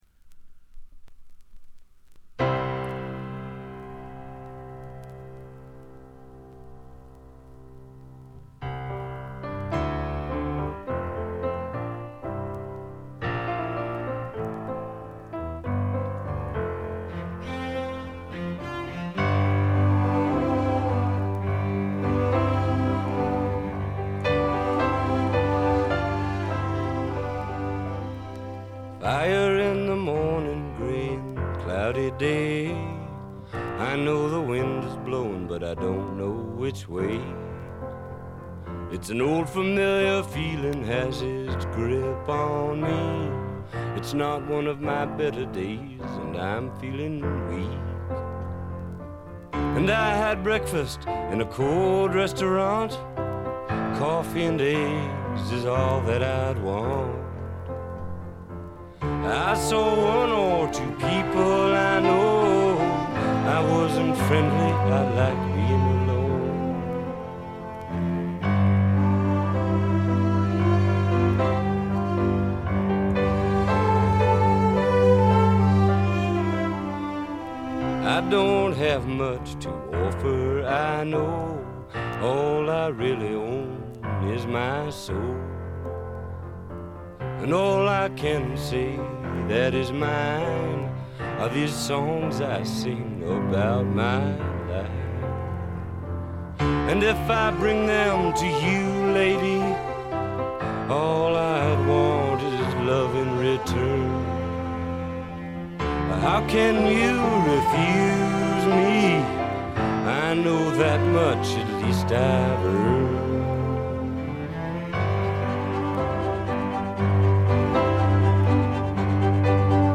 B1中盤とB2中盤にプツ音1回。
試聴曲は現品からの取り込み音源です。